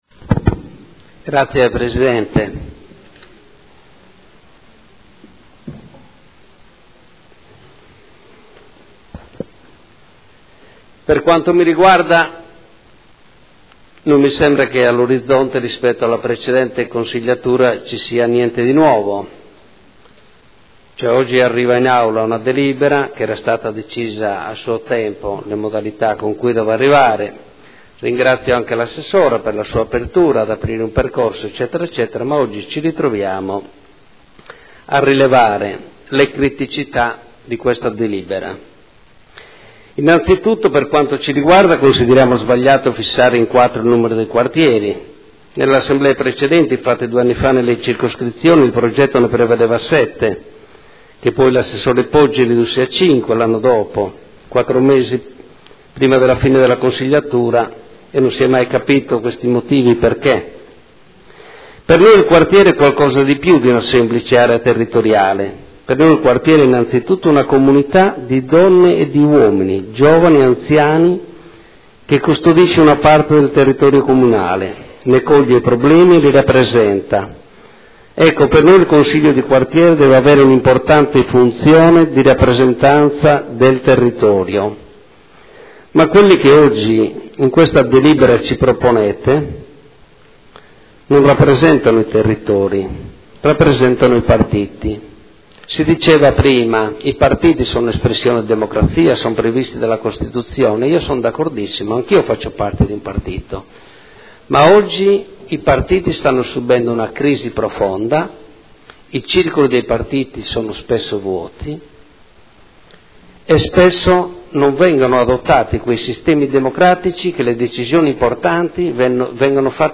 Marco Cugusi — Sito Audio Consiglio Comunale
Seduta del 16 ottobre. Proposta di deliberazione: Individuazione e nomina dei componenti dei Consigli di Quartiere (Conferenza Capigruppo del 16 ottobre 2014).